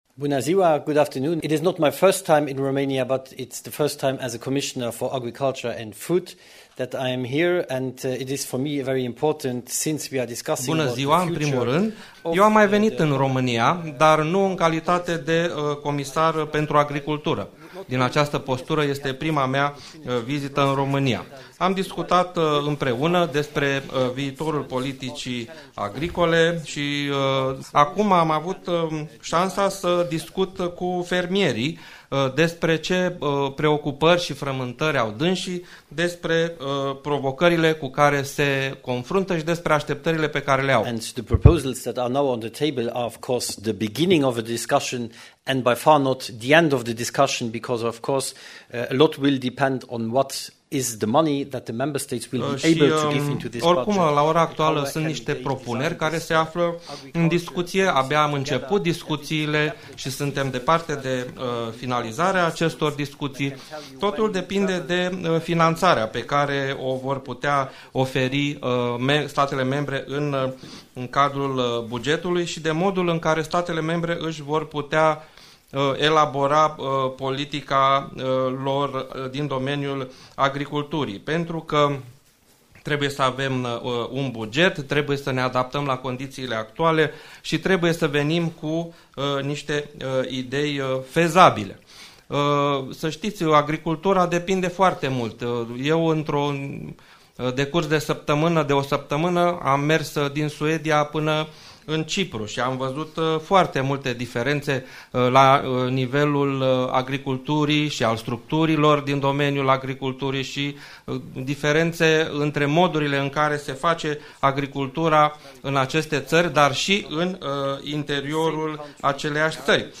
Christophe Hansen, Comisarul European pentru Agricultură, despre viitorul PAC – la Conferința AGRI4FUTURE
COMISAR-Christophe-HANSEN-montaj-tradus.mp3